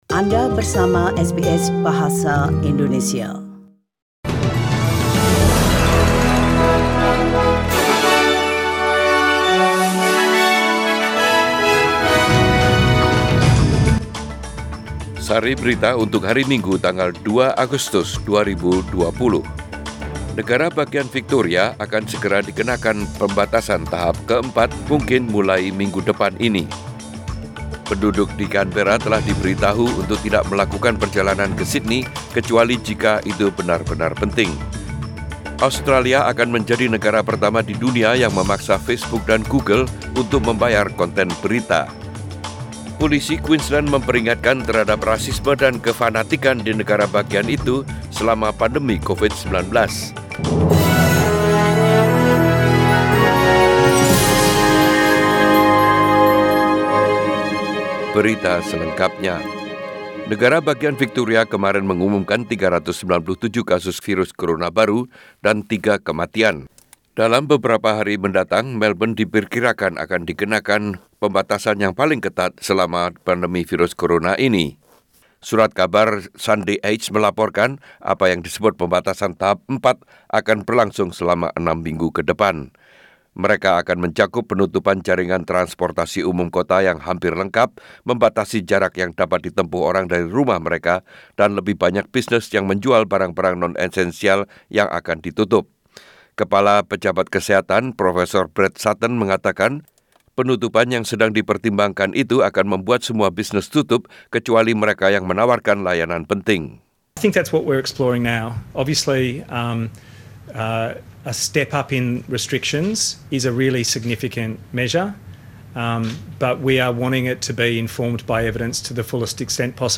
Warta Berita Radio SBS Program Bahasa indonesia - 2 Agustus 2020